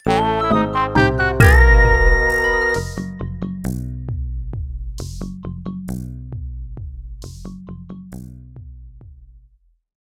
The jingle that plays when a boss grows big
Fair use music sample